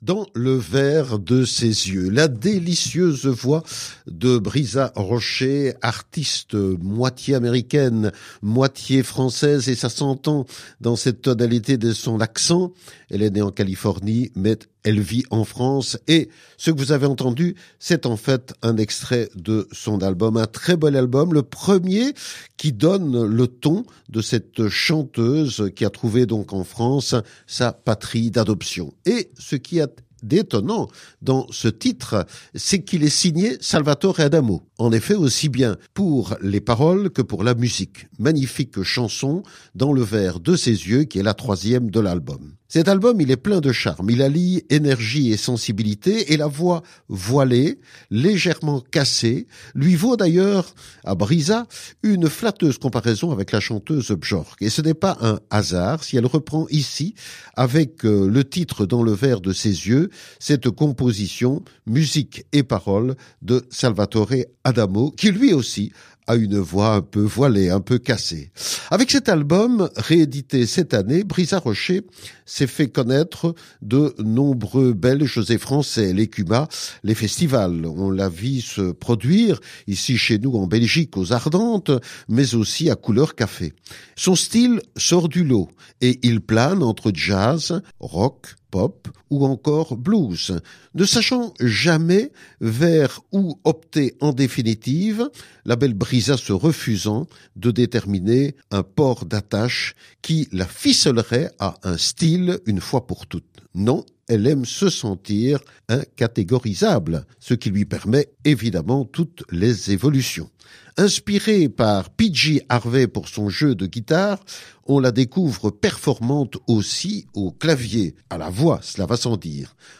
avec ses tonalités jazz.